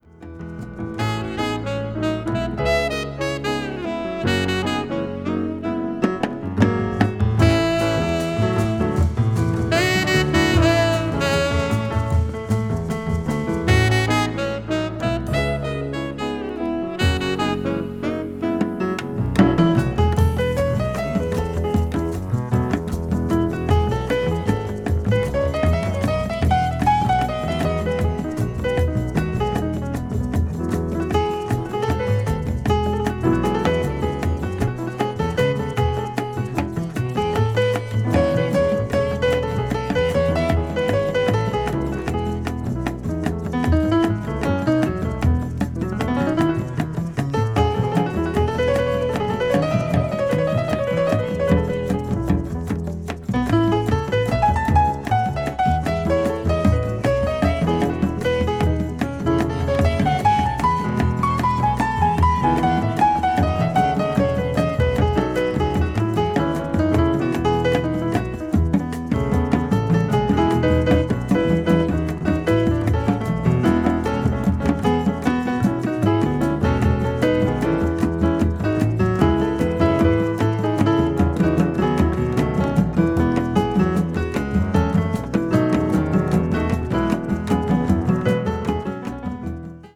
media : EX/EX(わずかにチリノイズが入る箇所あり)
A1/B2/B3で漂うどこかエスニックな雰囲気は、やはり北欧勢の感性によるものでしょうか。